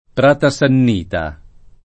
pr#ta Sann&ta] (Camp.), Pratavecchia [pratav$kkLa] (Piem.); talaltra come pl. f. (del masch. prato): le Prata [le pr#ta] (Tosc.)